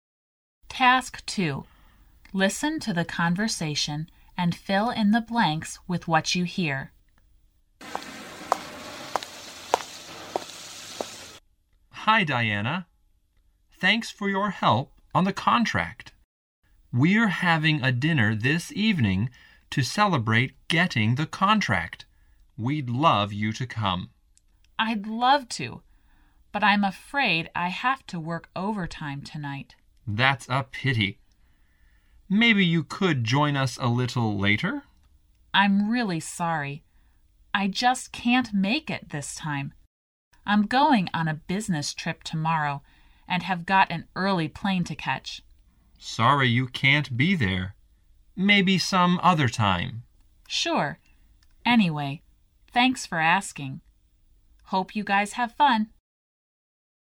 第一册英语单词朗读录音